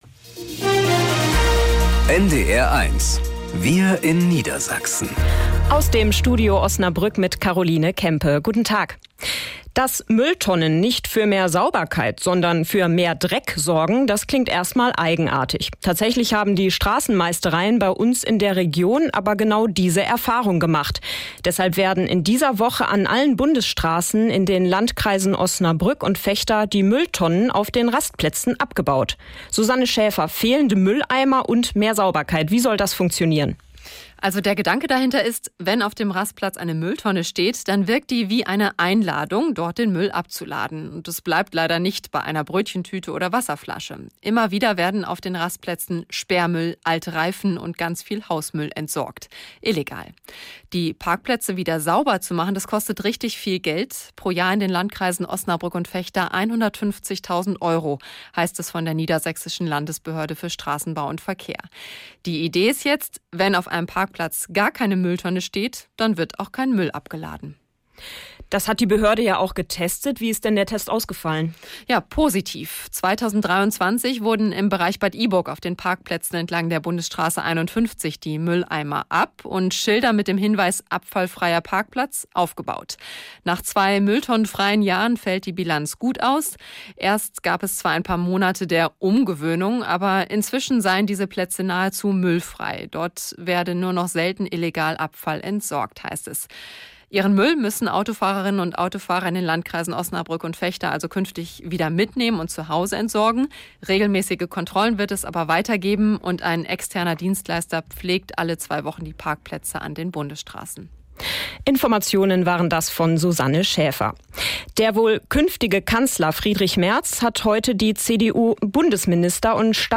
Wir in Niedersachsen - aus dem Studio Osnabrück | Nachrichten 13 subscribers updated 27d ago Abonnieren Abonniert Abspielen Abspielen Teilen Alle als (un)gespielt markieren ...